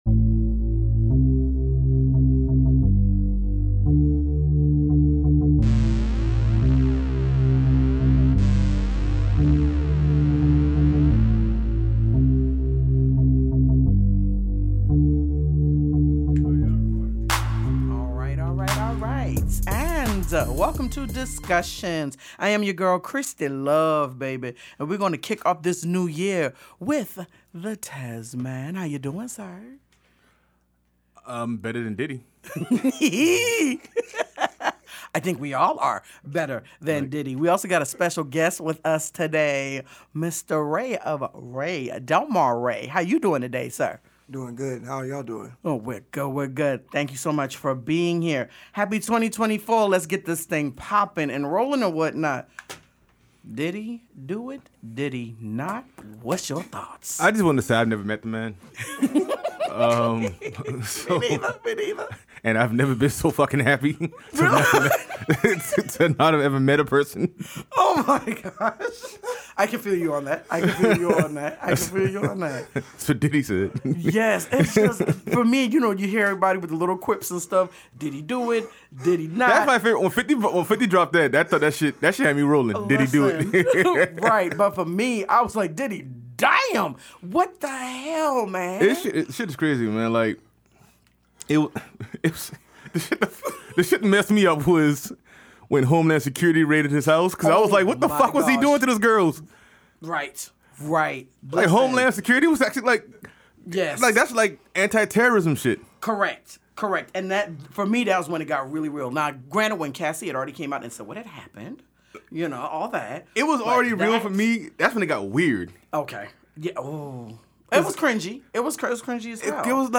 We record here at Lynx Recording Studios in Summerville, SC.